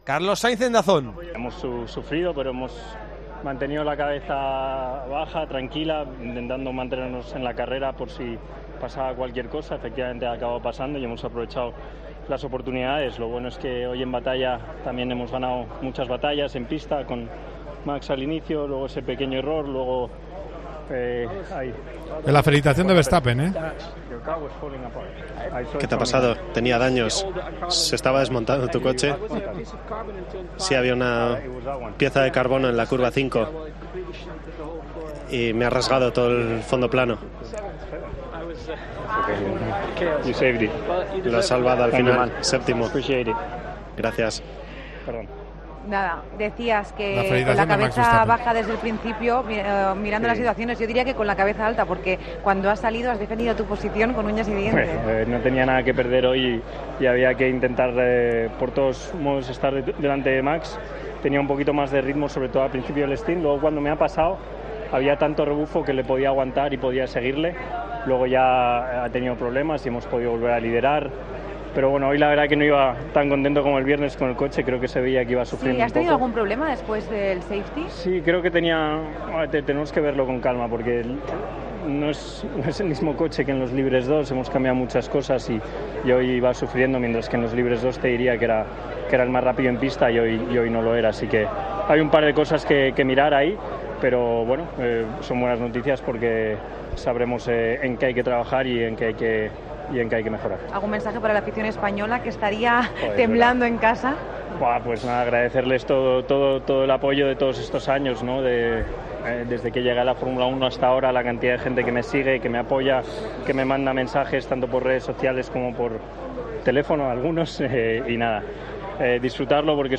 El piloto español hablño en Dazn tras ganar su primera carrera de Fórmula 1 en el circuito de Silverstone por delante de Hamilton y Checo Pérez.